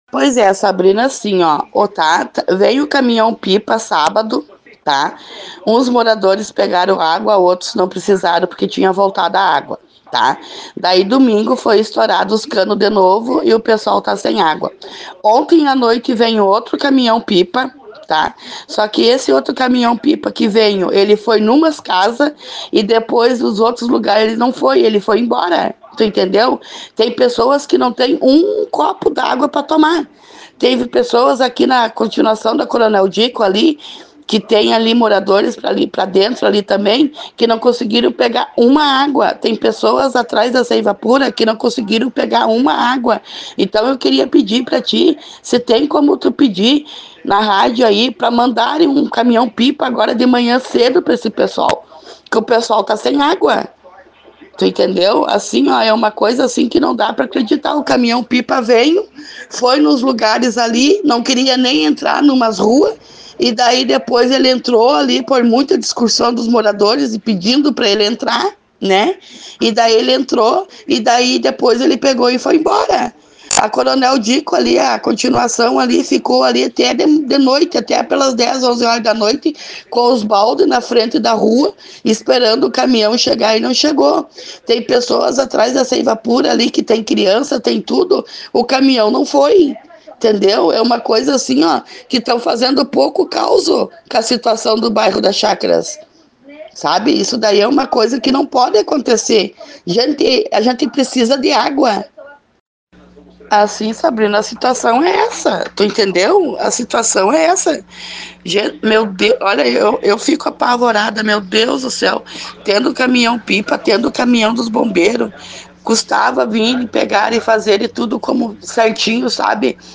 Ouça abaixo o relato da moradora: